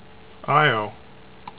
"EYE oh" ) is the fifth of Jupiter's known satellites and the third largest; it is the innermost of the Galilean moons.